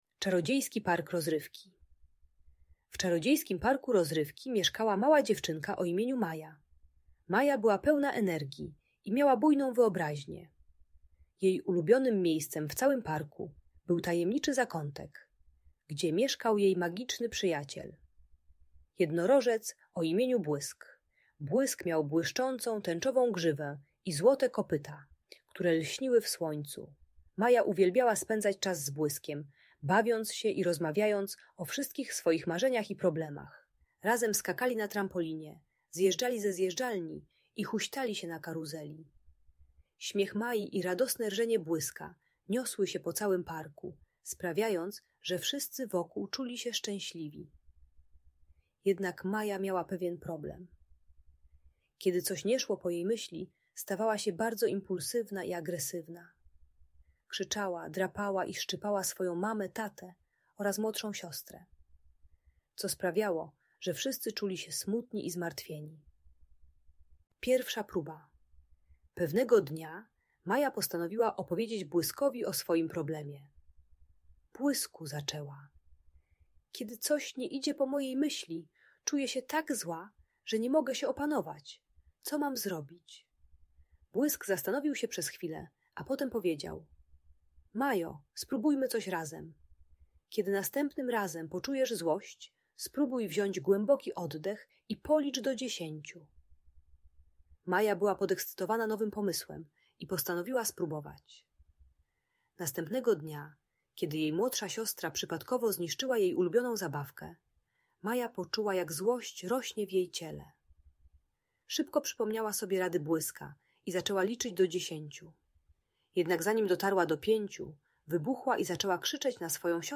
Czarodziejski Park Rozrywki - Magiczna historia Mai i Błyska - Audiobajka